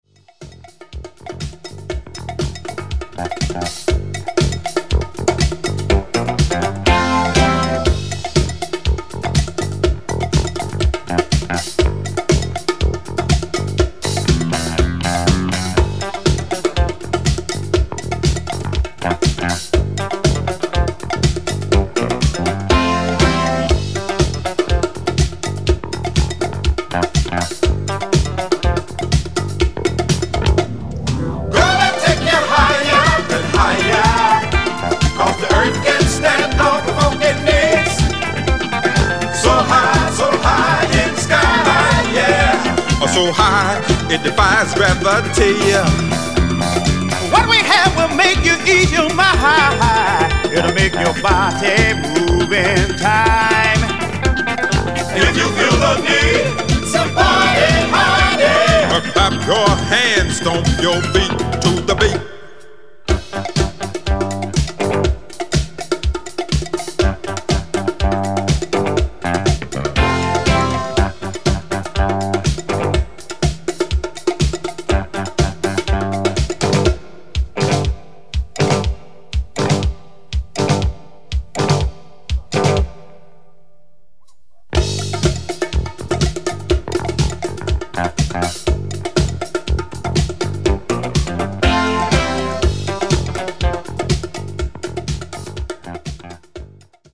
a funk band
Latin funk disco